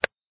click_on_wood_2.ogg